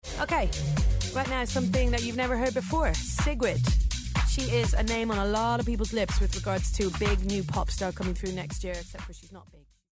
読み方
シグリッド
BBC Radio 1 Annie Macの発音